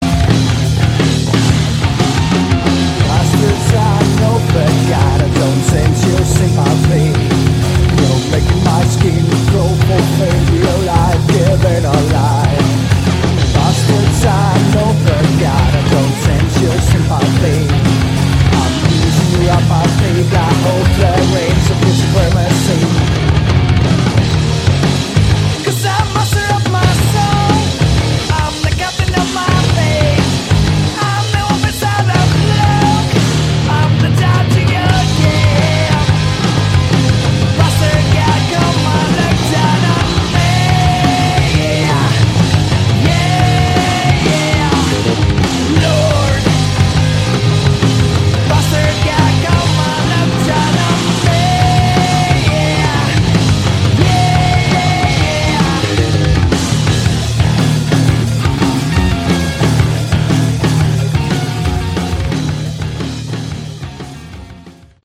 Category: Modern Hard Rock/Punk